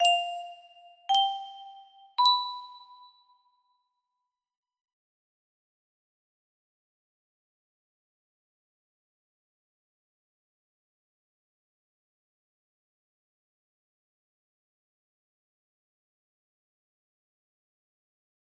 10500 music box melody
Grand Illusions 30 (F scale)